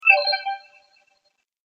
Download League of Legends Message sound effect for free.